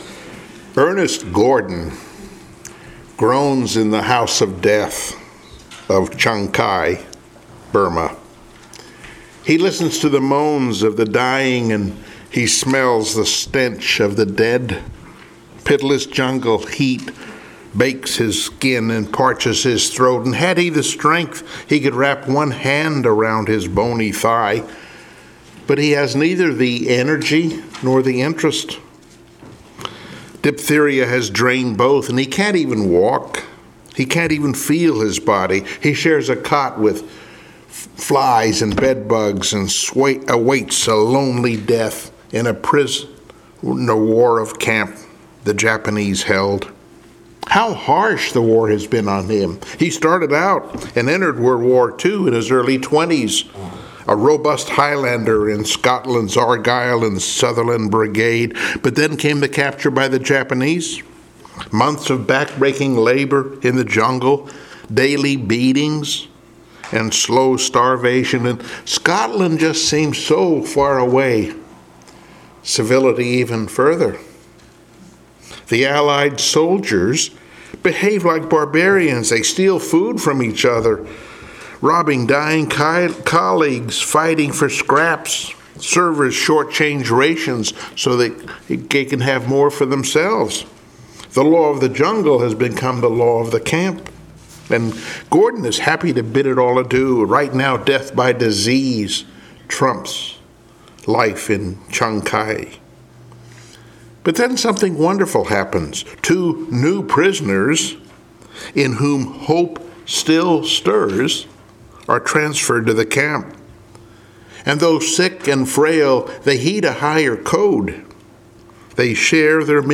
I Samuel 25 Service Type: Sunday Morning Worship 18 Abigail acted quickly.